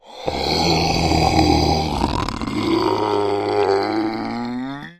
描述：5秒的深沉，嘶嘶和喘息，怪物呻吟就是这个文件。
Tag: 呻吟 僵尸